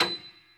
55p-pno40-B6.wav